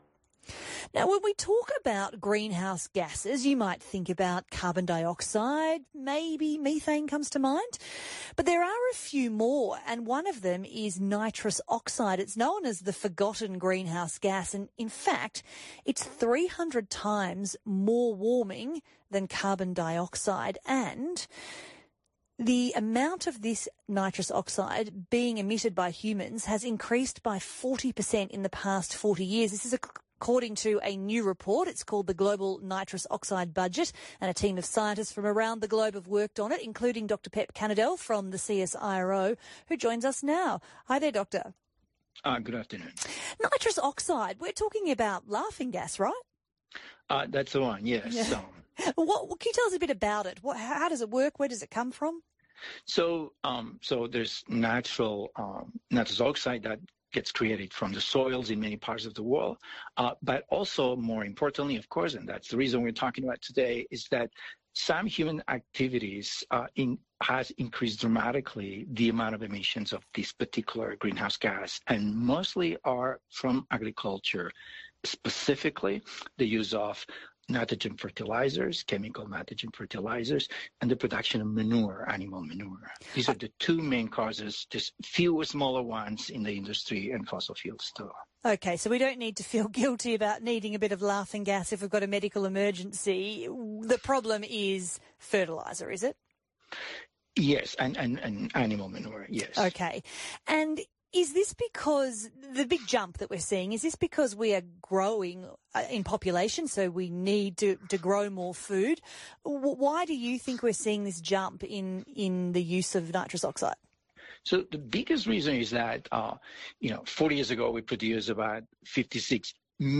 Interviews ABC Radio